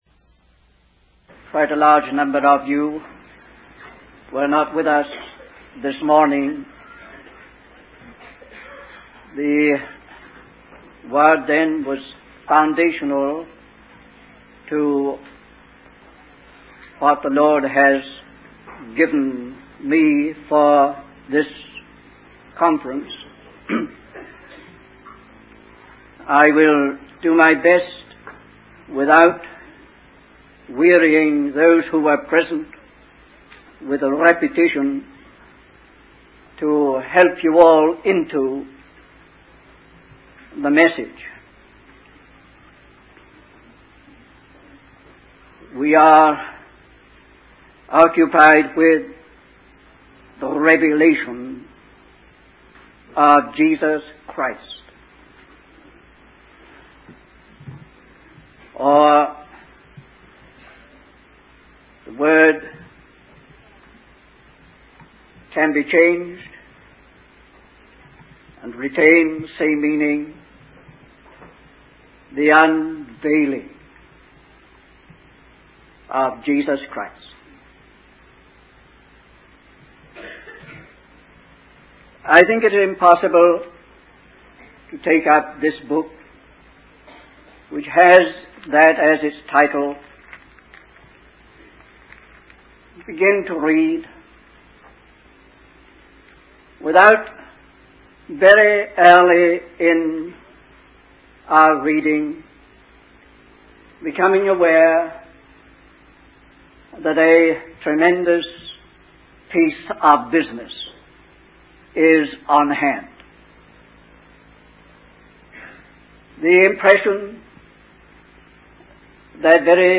In this sermon, the speaker emphasizes the significance and importance of the book of Revelation.